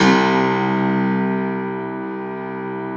53a-pno02-C0.aif